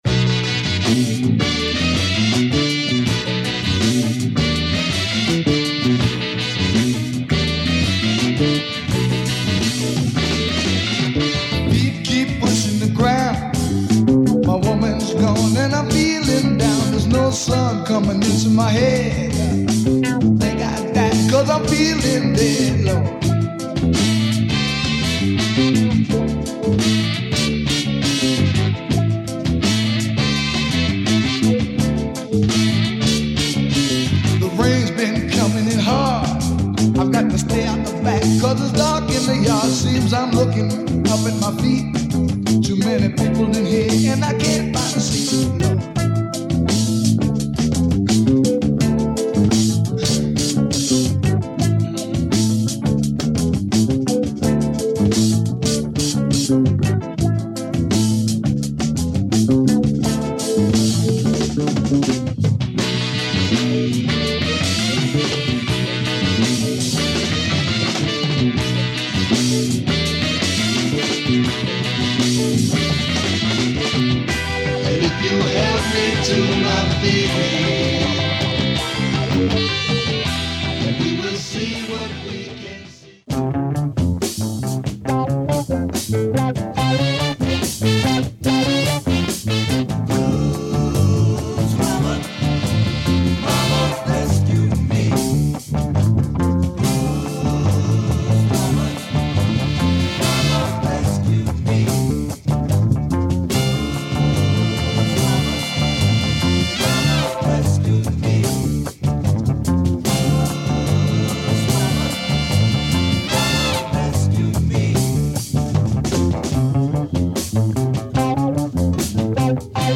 Supra cult Mexican funk / psych soul